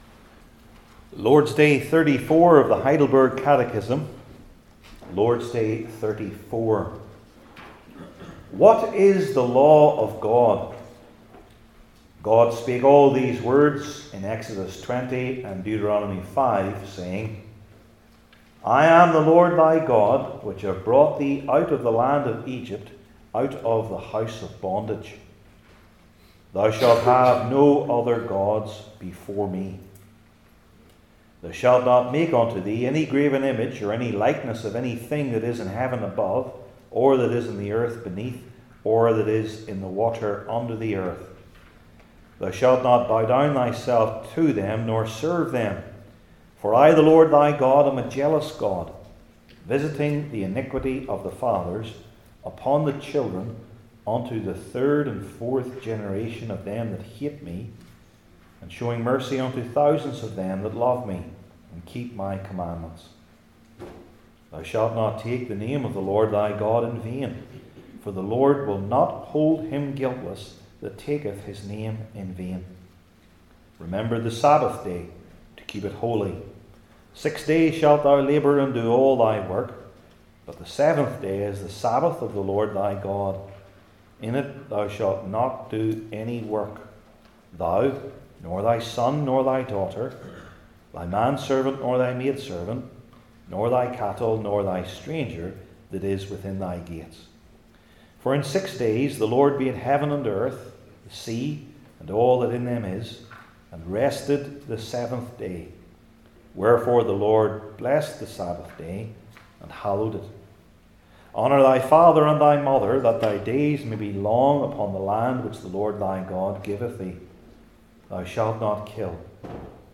Heidelberg Catechism Sermons I. What We Must Not Do II.